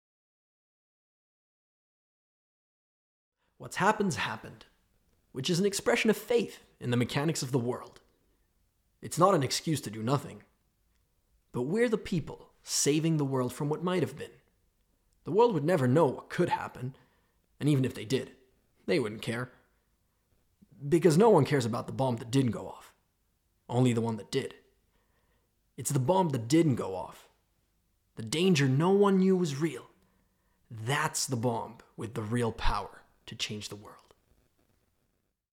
Bande Démo: Voix-Off
16 - 35 ans - Baryton